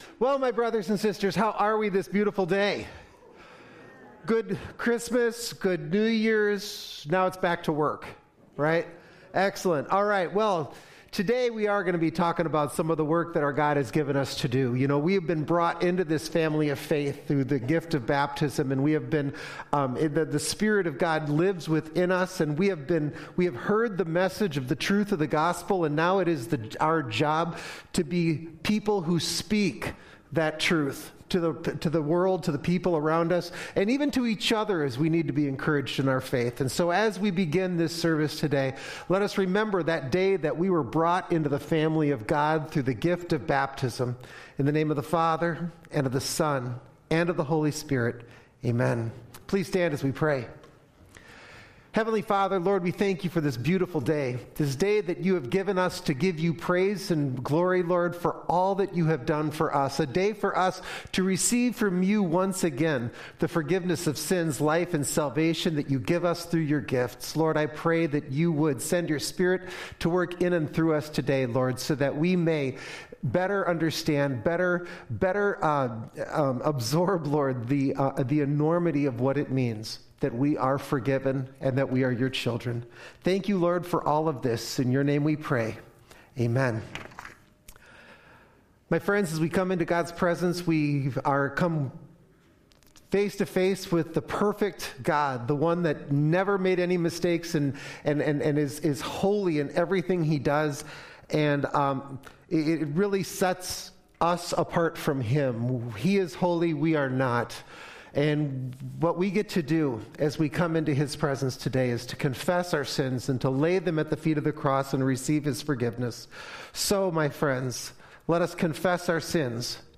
2024-January-7-Complete-Service.mp3